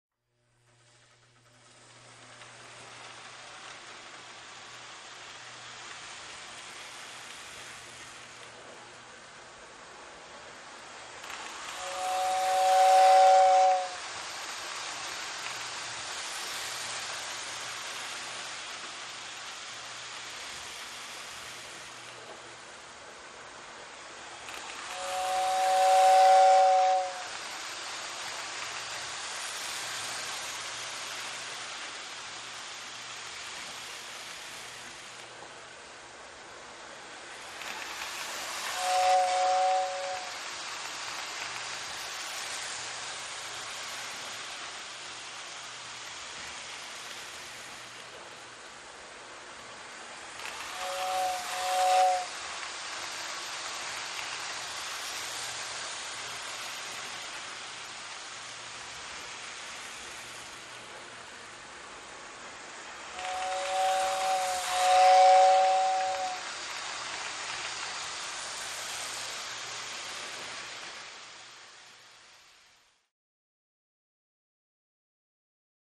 R / C Train; Toy Electric Train Runs Around Track, With Occasional Whistle.